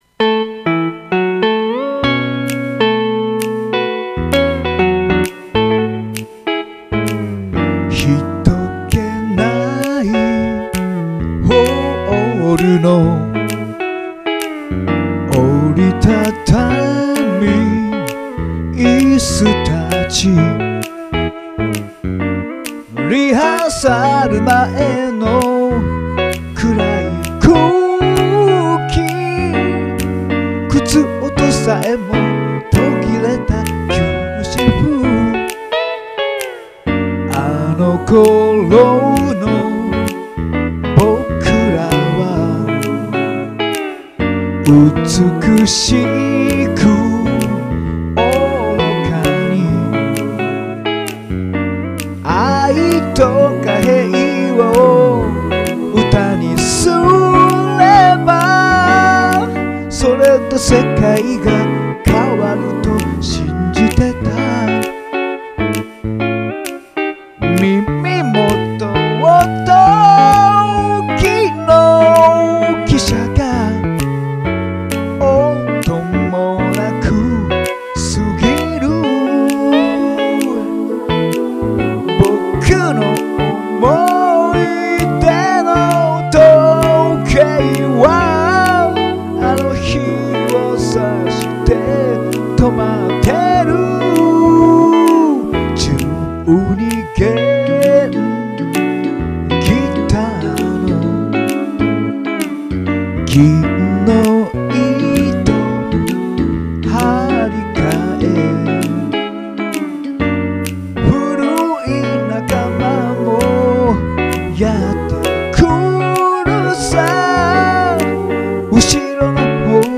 JOU SOUND でホームカラオケしたものを録音いたしまして、
あの頃に青春を過ごした方には、胸に染み入るバラードです。